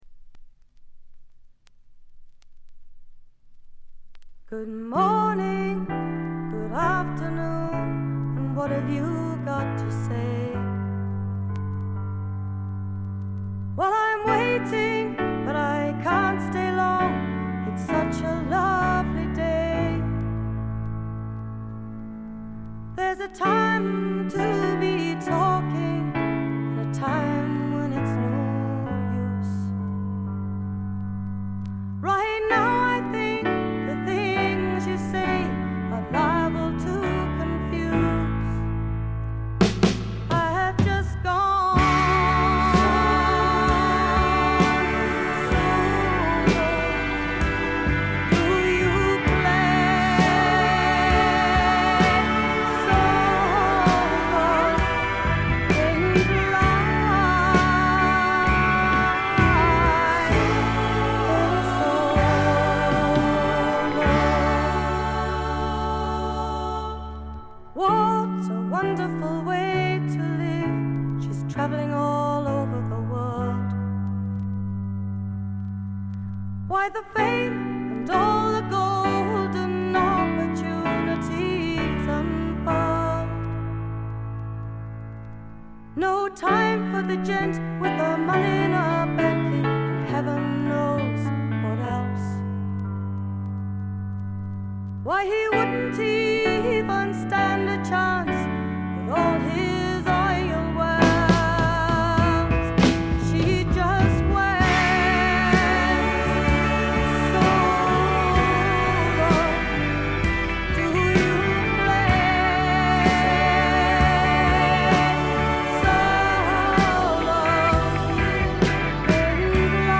ところどころでチリプチがやや目立ちますが凶悪なノイズはありません。
1stのようないかにもな英国フォークらしさは影を潜め、オールドタイミーなアメリカンミュージック風味が加わってきています。
試聴曲は現品からの取り込み音源です。
vocals, piano, acoustic guitar